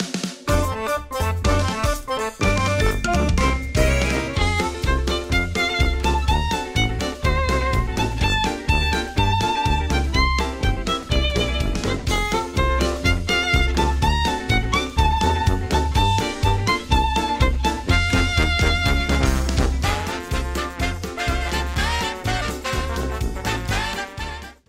Edited Trimmed, added fade out